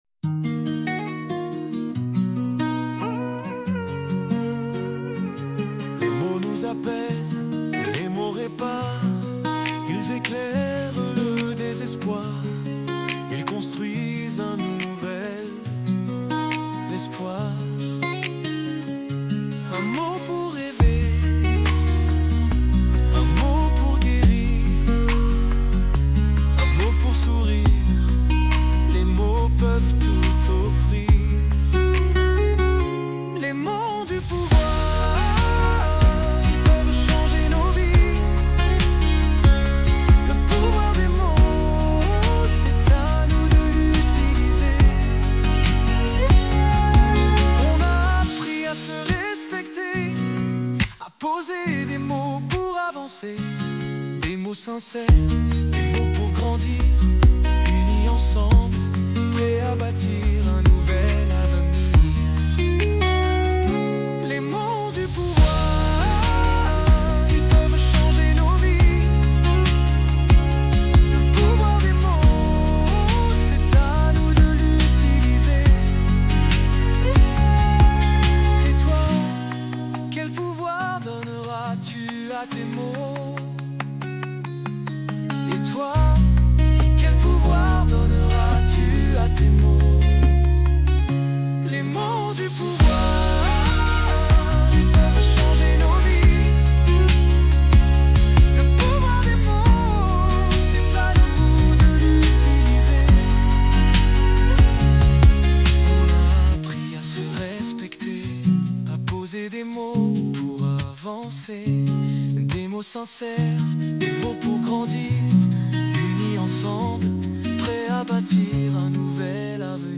07-chant-final-voix-basse.mp3